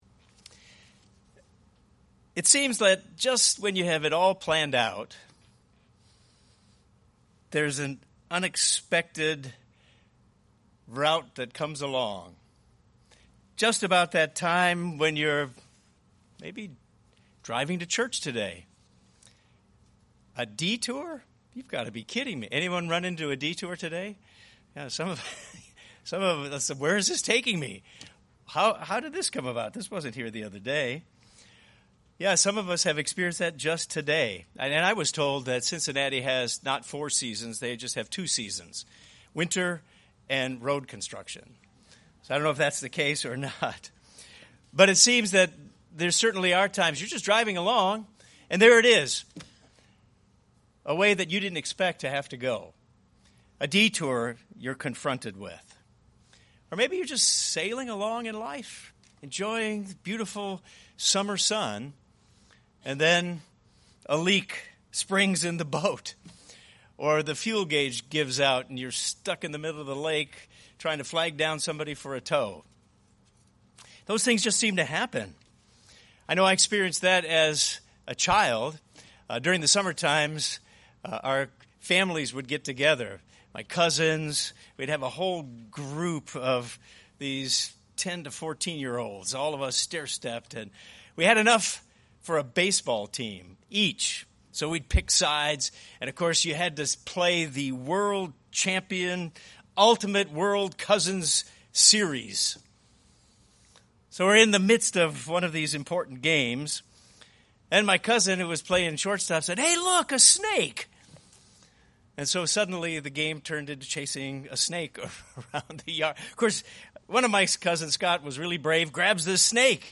This sermon addresses God’s perspective as he leads our life and lessons to learn about his guidance.